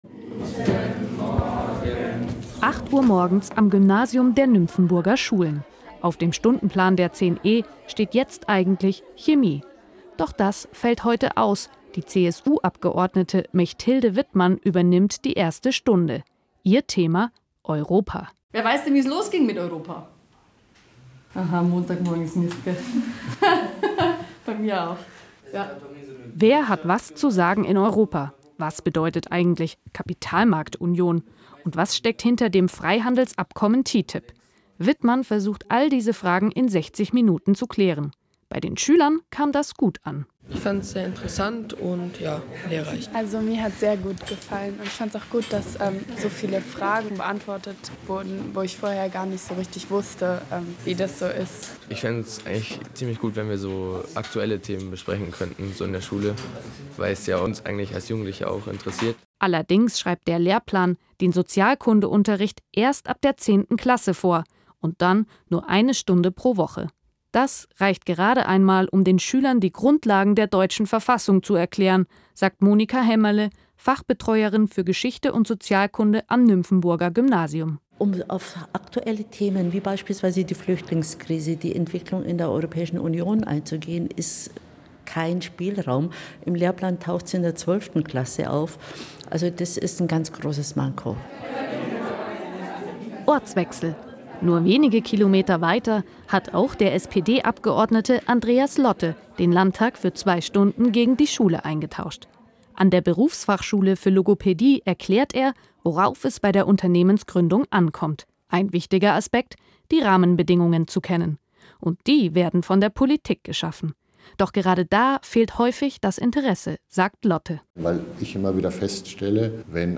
Die Landtagsabgeordnete zu Gast im Nymphenburger Gymnasium